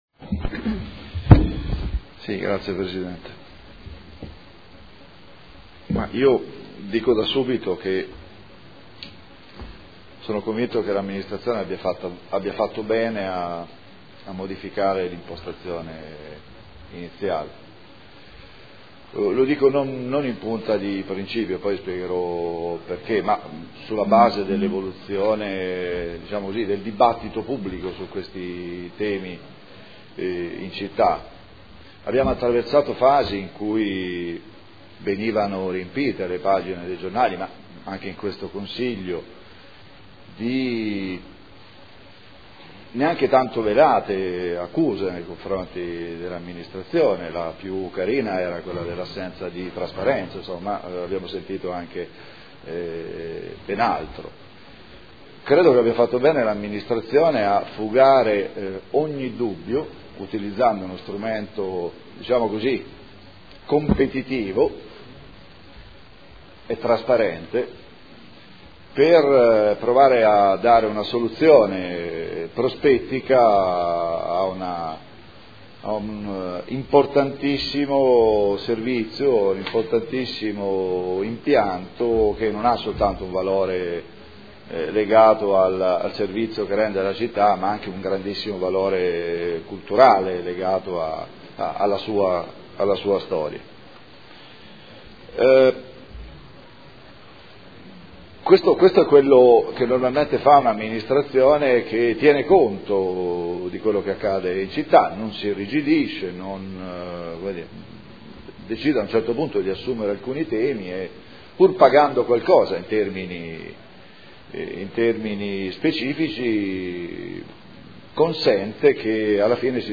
Seduta del 16 gennaio. Proposta di deliberazione: Concessione gestione piscina Dogali: indirizzi. Dibattito